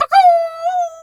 chicken_2_bwak_07.wav